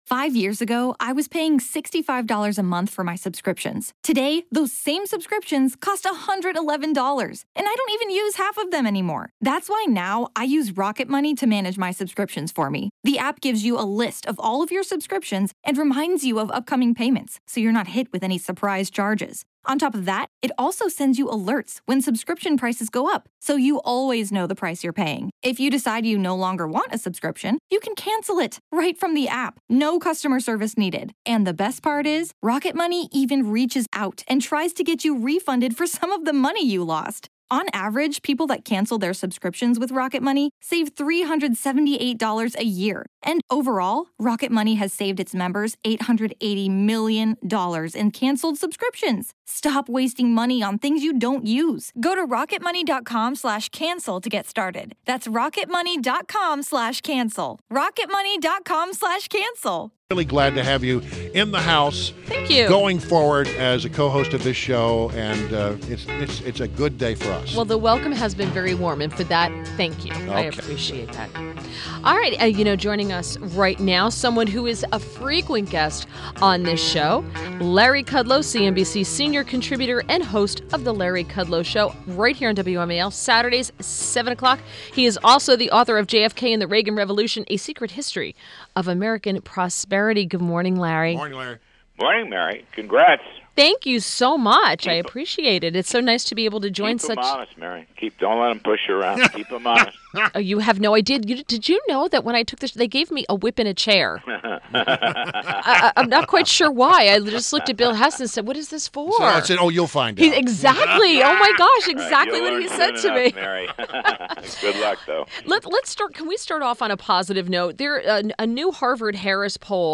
WMAL Interview - LARRY KUDLOW - 02.21.17